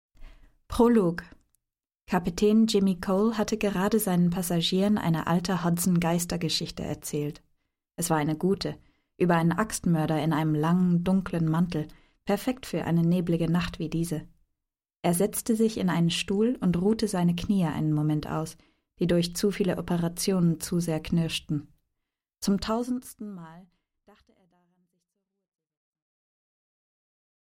Аудиокнига Gefesselt | Библиотека аудиокниг